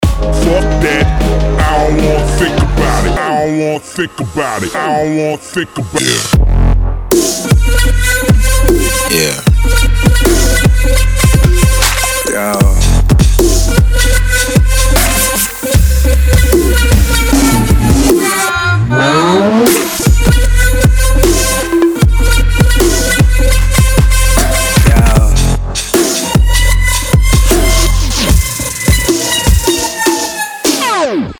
dance
Trap
twerk